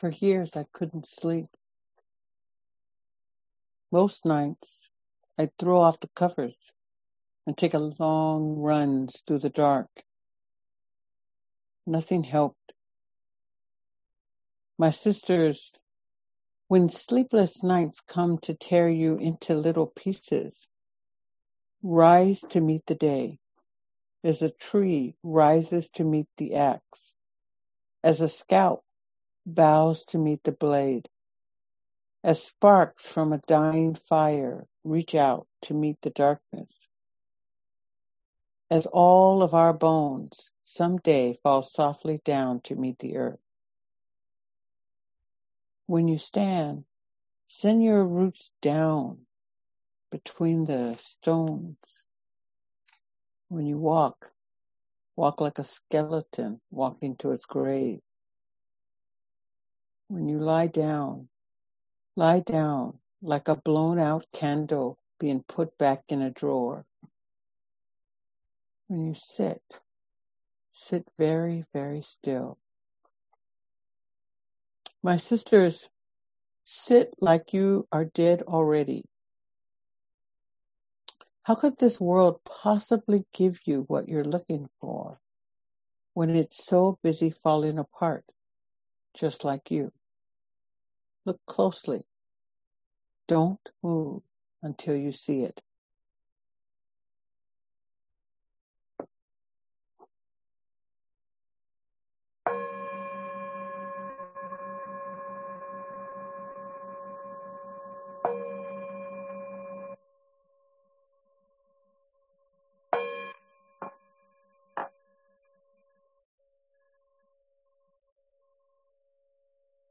Clinging: Talk 2 recap & discussion
2020-09-28 Venue: Meeting Online with Zoom